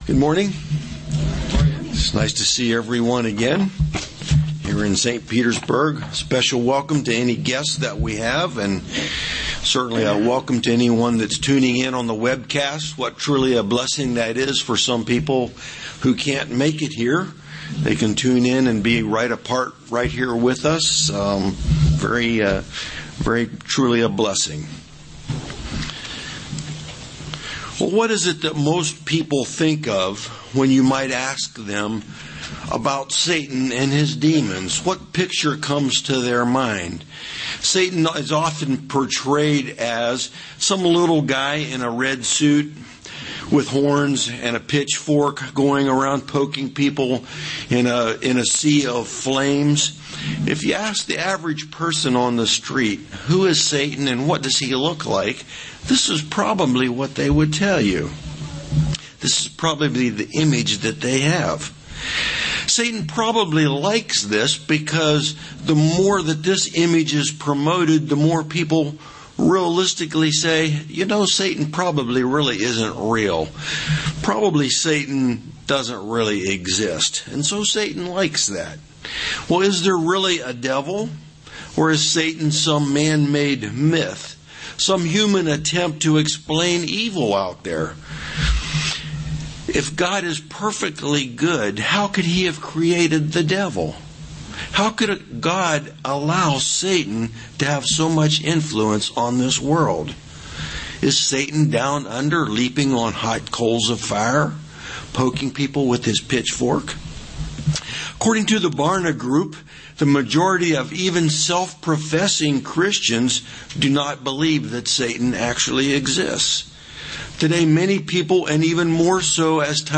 Given in St. Petersburg, FL
Print Today we are going to review the fundamental doctrine of the church UCG Sermon Studying the bible?